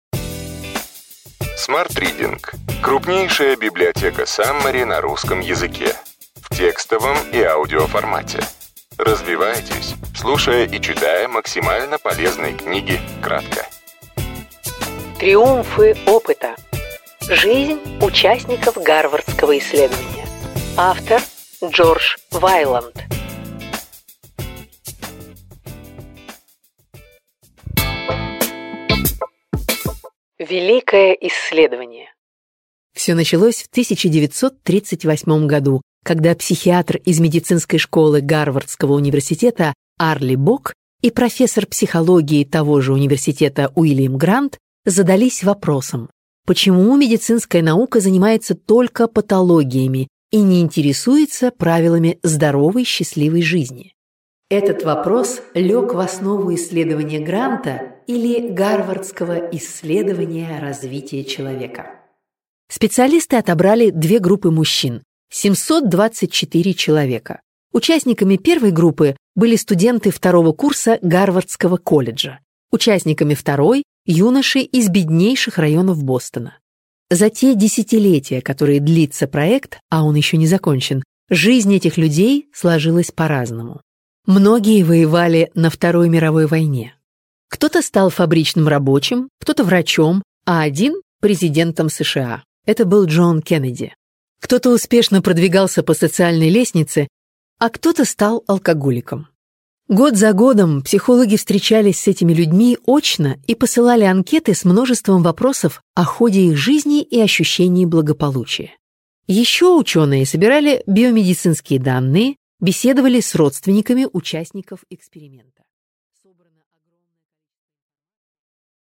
Аудиокнига Ключевые идеи книги: Триумфы опыта. Жизнь участников Гарвардского исследования.